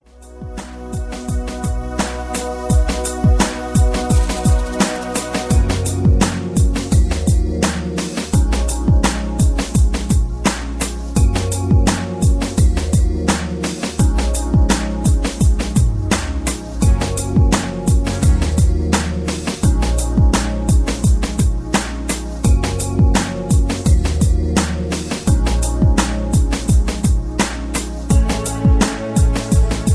Karaoke MP3 Backing Tracks
Just Plain & Simply "GREAT MUSIC" (No Lyrics).